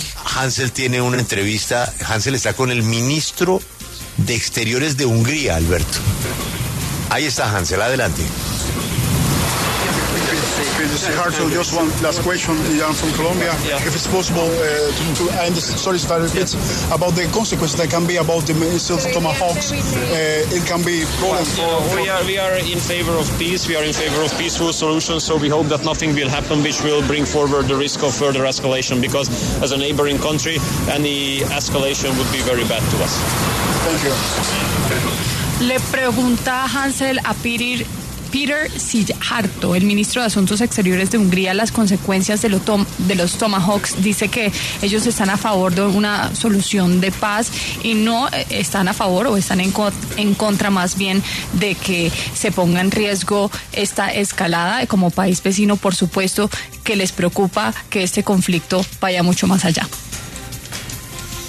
Peter Szijarto, ministro de Asuntos Exteriores de Hungría, habló en La W sobre los misiles Tomahawk estadounidenses que Ucrania está solicitando al presidente Trump.
Desde la Semana rusa de la Energía, La W conversó con Peter Szijarto, ministro de Asuntos Exteriores de Hungría, quien asistió a este evento que tiene como objetivo presentar el potencial de Rusia en el sector de energía.